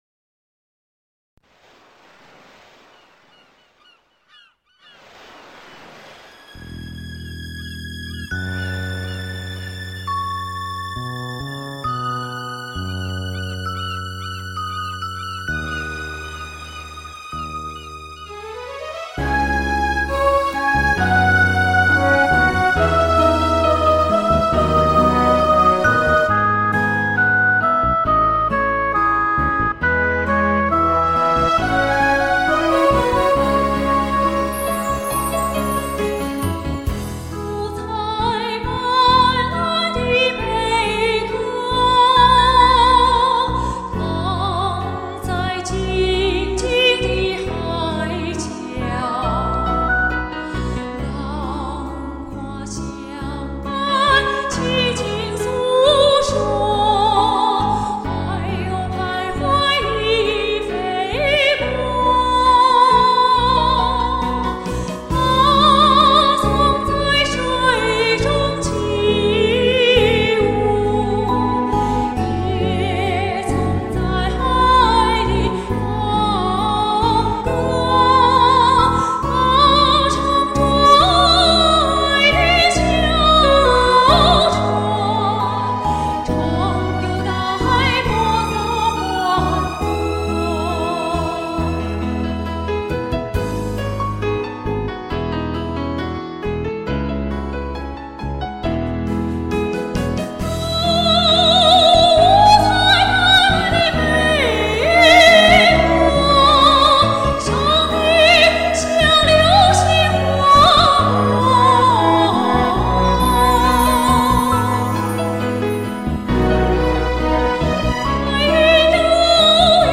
声音甜润柔美，音乐形象把握准确，演唱情绪很到位，细腻中不乏激情, 歌声中透着青春的活力, 十分精彩。